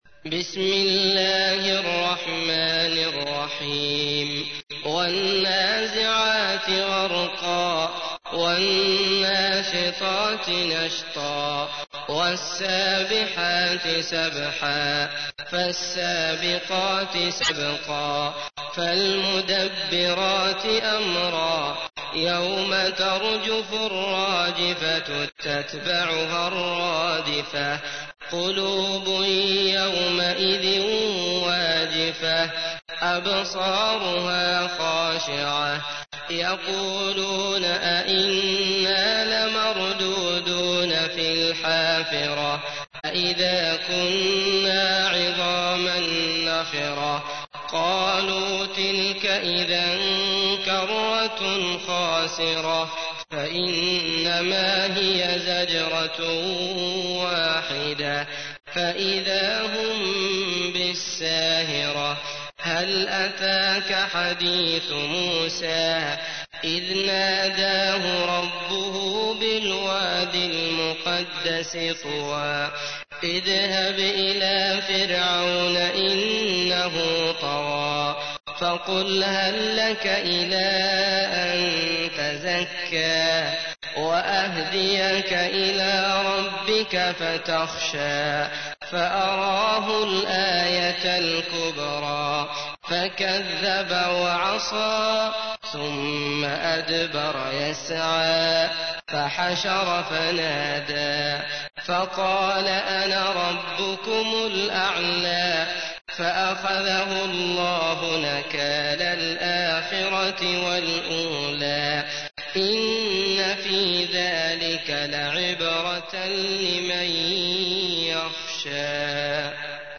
تحميل : 79. سورة النازعات / القارئ عبد الله المطرود / القرآن الكريم / موقع يا حسين